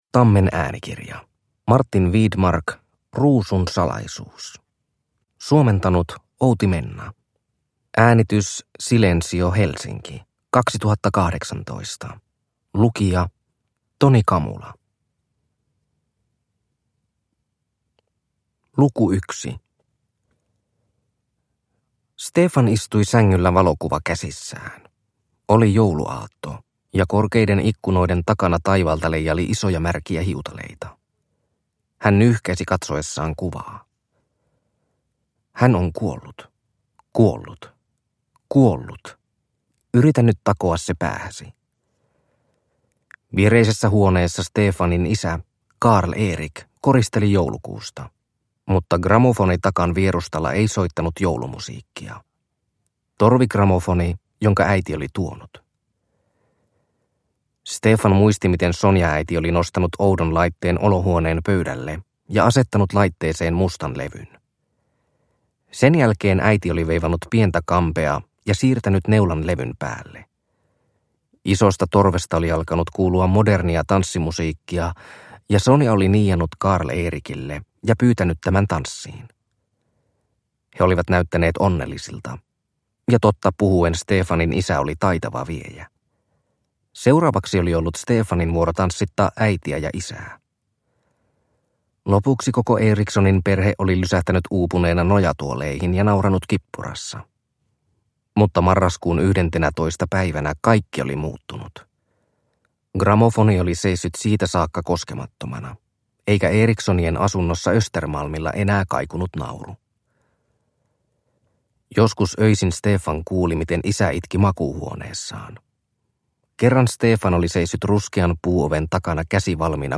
Ruusun salaisuus – Ljudbok